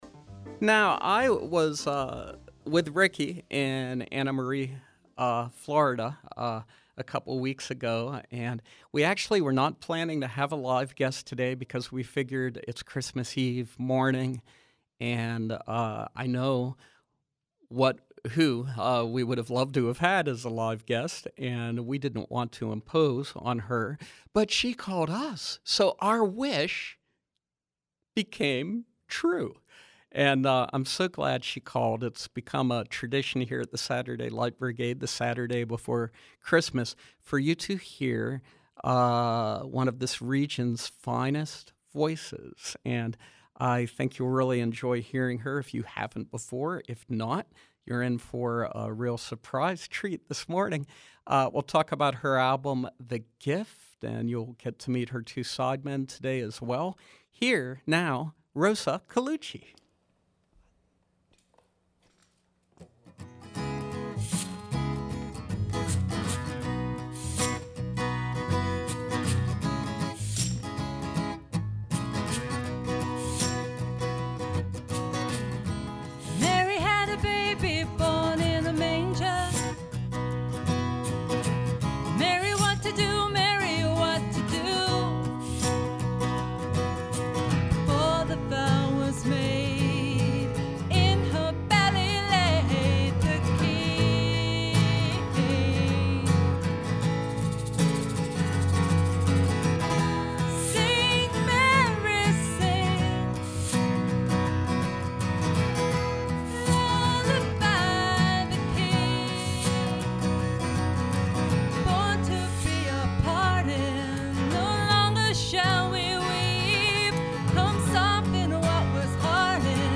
Live Music
the very talented singer-songwriter
live in our studios.